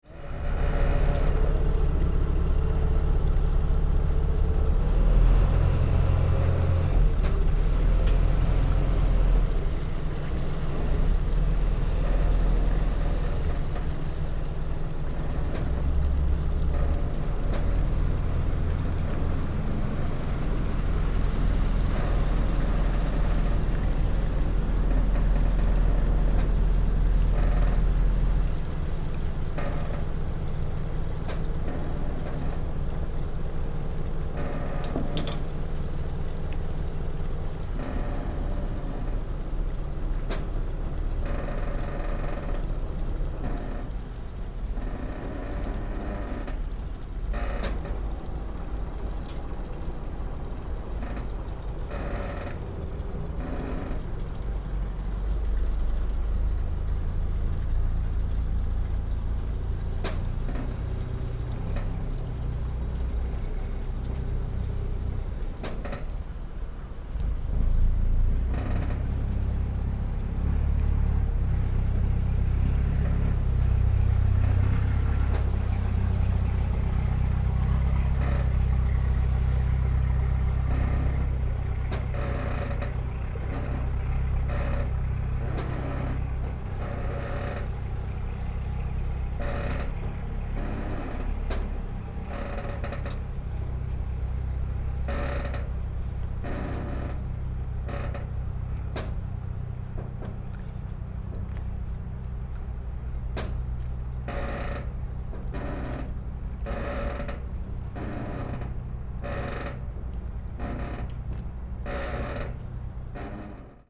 Aberdeen fish market ferry, Hong Kong